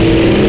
engine8.wav